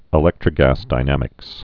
(ĭ-lĕktrə-găsdī-nămĭks)